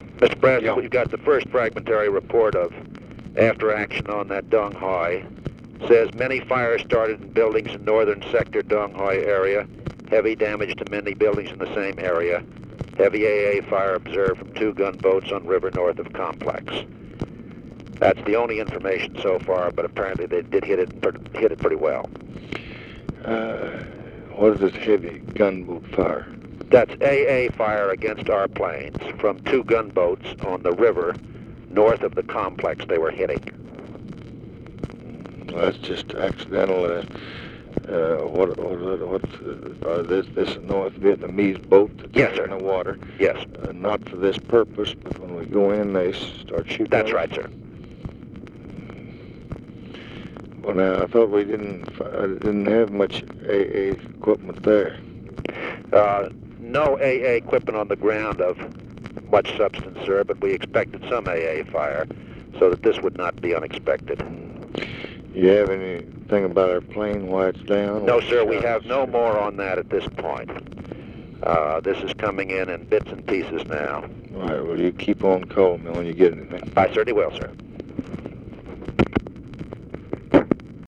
Conversation with CYRUS VANCE, February 7, 1965
Secret White House Tapes